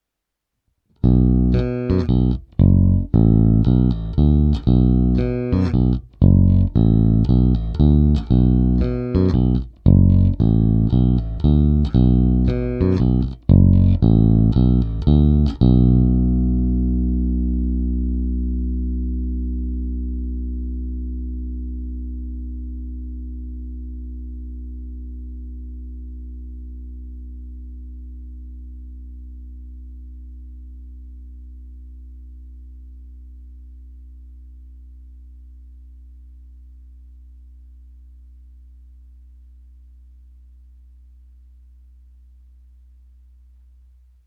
Není-li uvedeno jinak, ukázky jsou provedeny rovnou do zvukové karty a jen normalizovány. Hráno vždy nad snímačem.
Korekce ve střední poloze